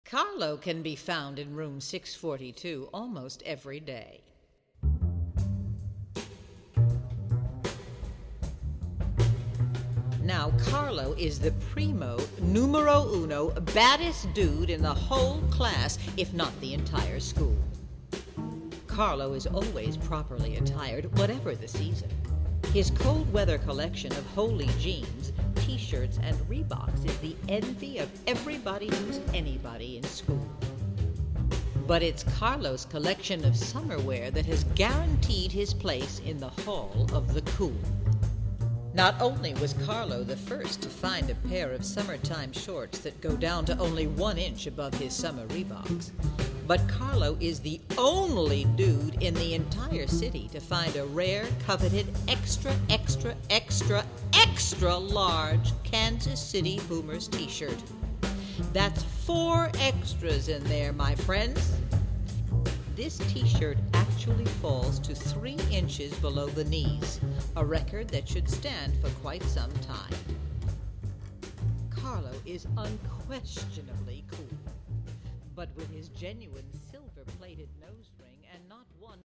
Please note: These samples are not of CD quality.